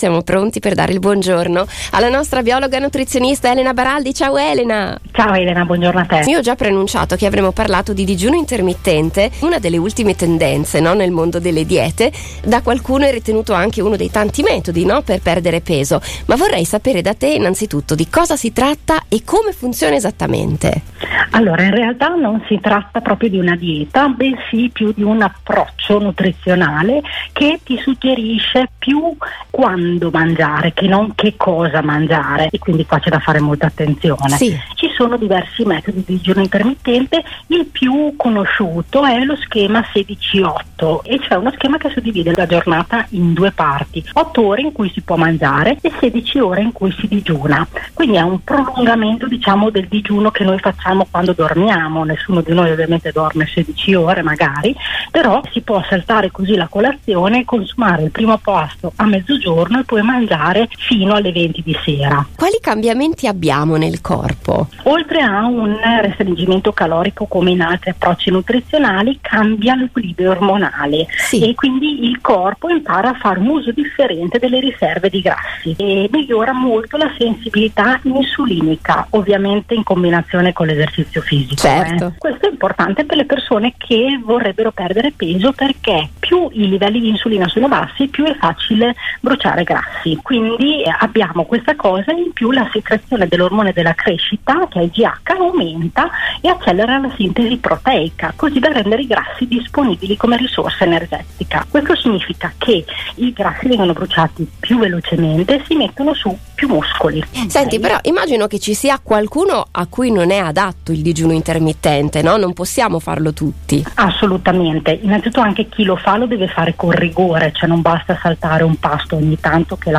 nutrizionista-2.mp3